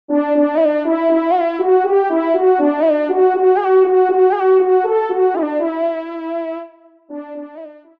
FANFARE
Extrait de l’audio « Ton de Vènerie »
Pupitre de Chant